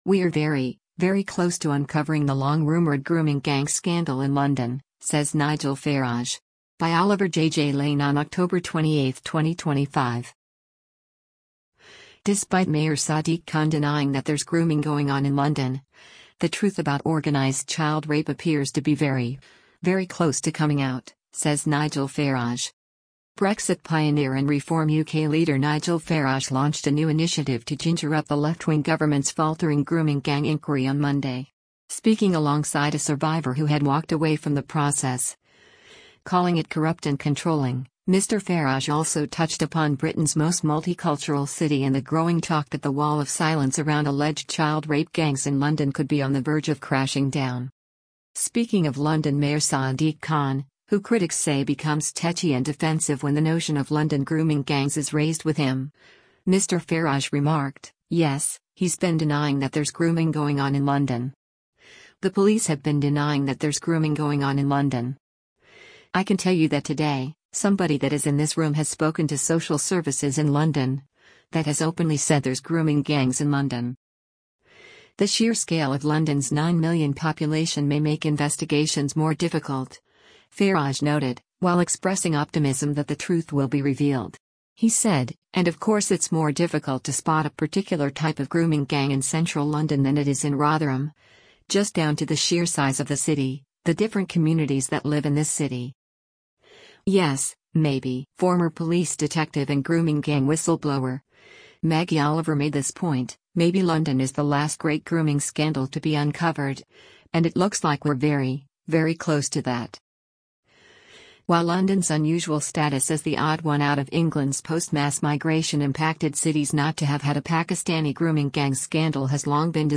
LONDON, UK - OCTOBER 27: Reform UK leader Nigel Farage addresses the media during a press